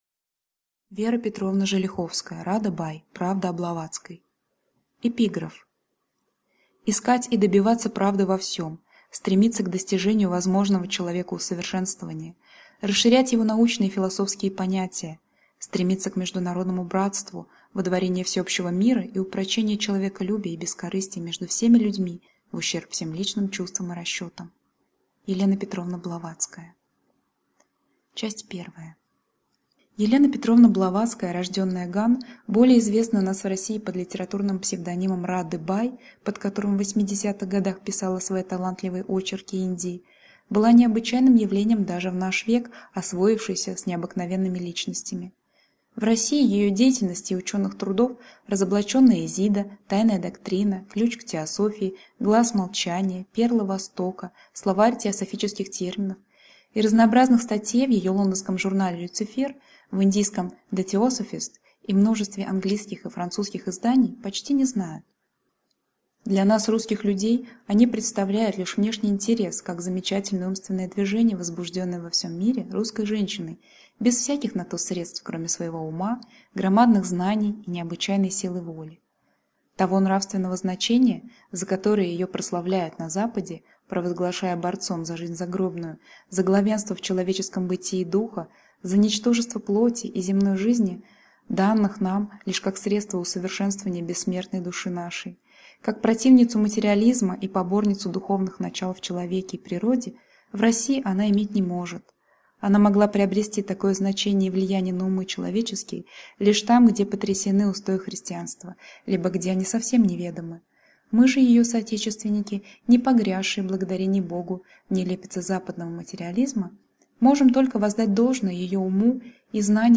Аудиокнига Радда-Бай: правда о Блаватской | Библиотека аудиокниг